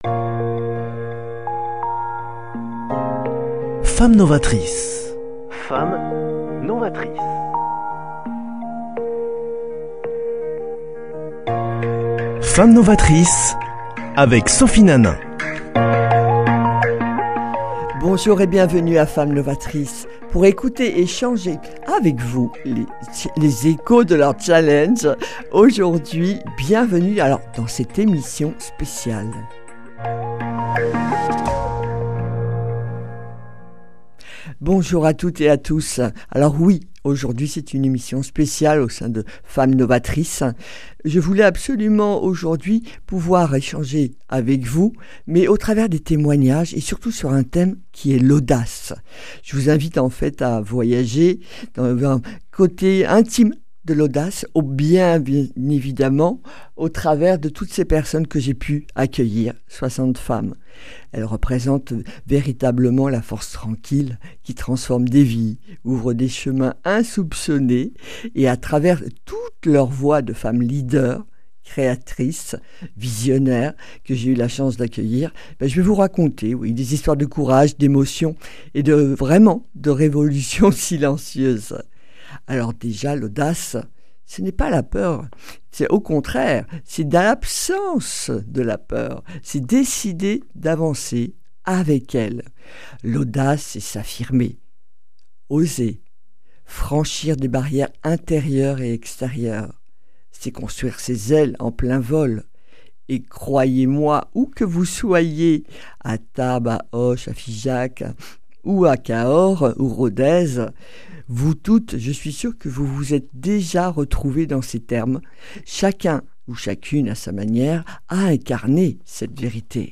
Mon émission sur l’audace pour lancer le printemps C’est moi qui vous parle, qui vous transmet un message à vous tous !